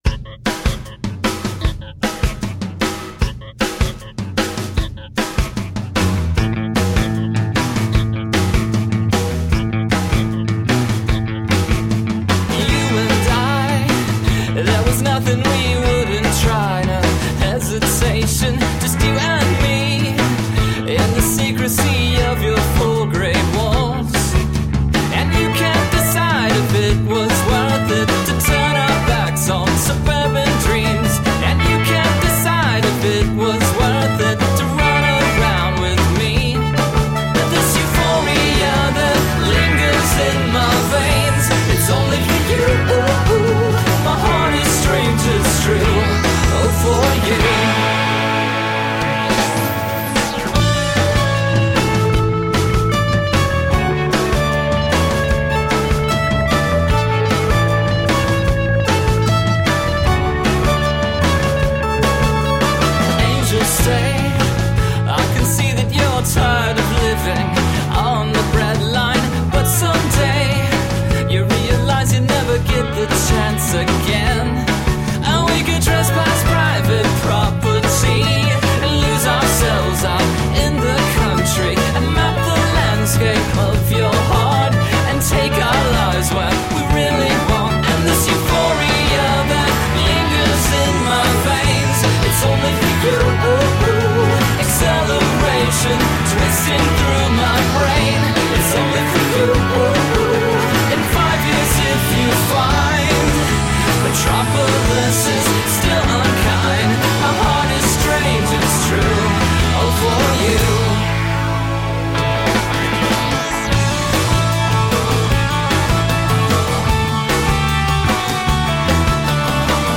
Up-beat rock and new wave for the 21st century.
Tagged as: Alt Rock, Pop